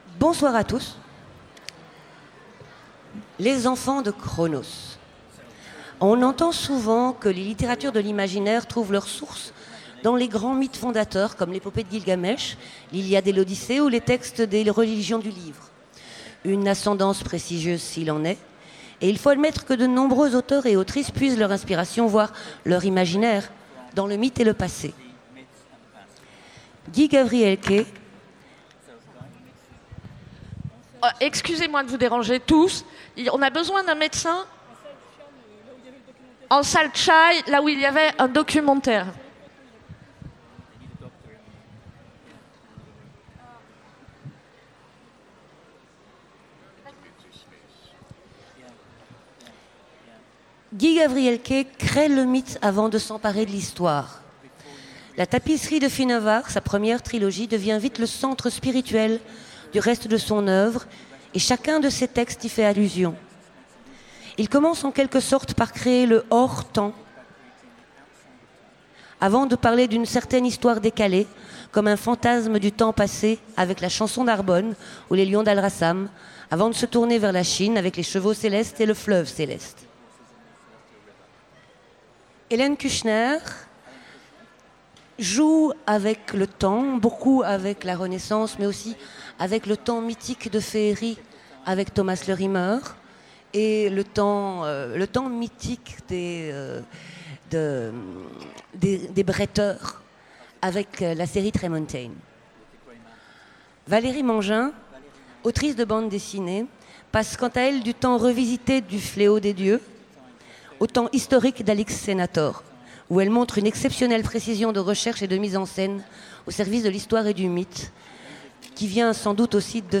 Utopiales 2017 : Conférence Les enfants de Chronos